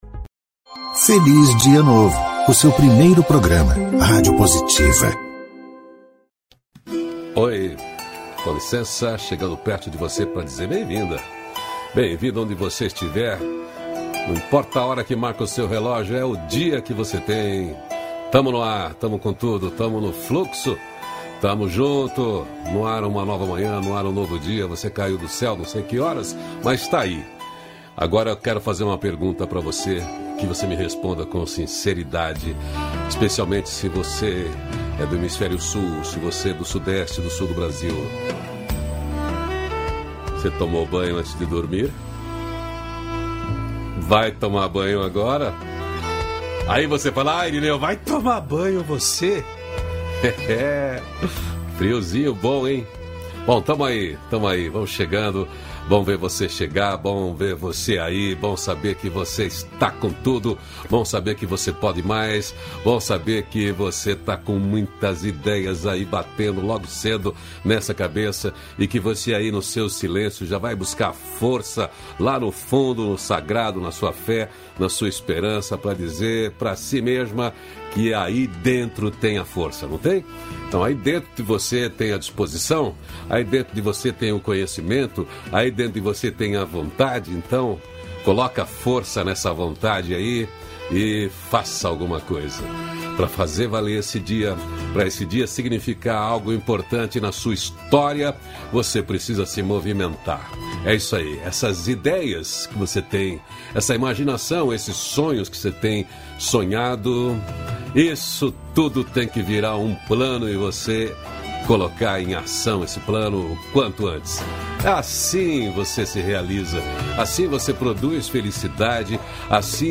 -521FelizDiaNovo-Entrevista.mp3